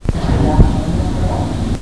Escuche espectrales voces del mas alla. Estas son la llamadas Psicofonias. Voces de fanatsmas capturadas en radios , televisores sin señal y corrientes de agua.
Otro hombre que al parecer no le agrada la muerte dice la Jodida muerte como respuesta a una pregunta de un investigador.